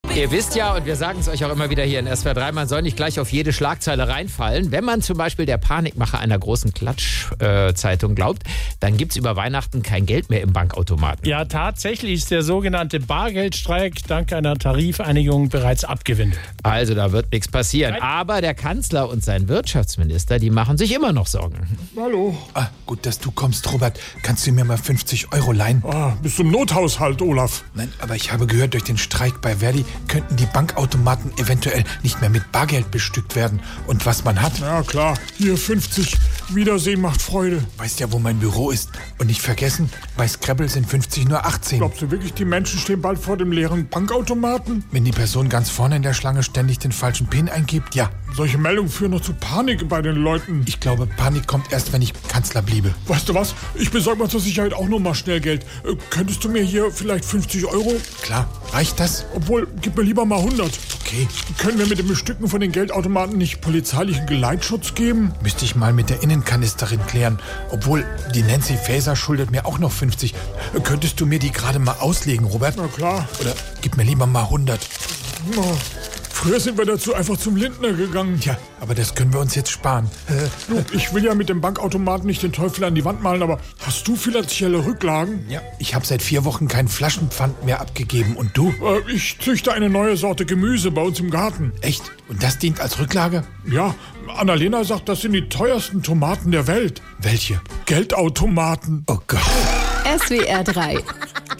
SWR3 Comedy Die Angst vor dem leeren Bankomaten